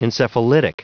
Prononciation du mot encephalitic en anglais (fichier audio)